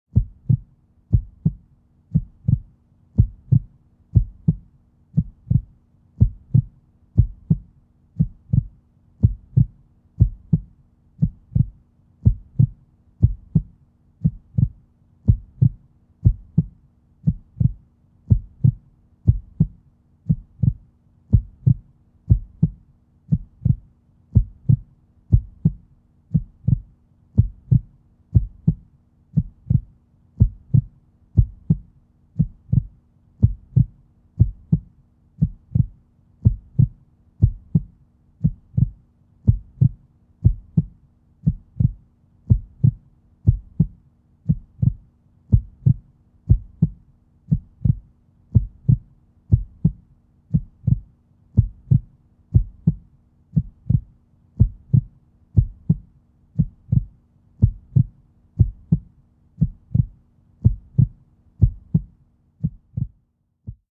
دانلود آهنگ تپش قلب از افکت صوتی انسان و موجودات زنده
دانلود صدای تپش قلب از ساعد نیوز با لینک مستقیم و کیفیت بالا
جلوه های صوتی